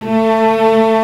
Index of /90_sSampleCDs/Roland L-CD702/VOL-1/CMB_Combos 1/CMB_Brite Strngs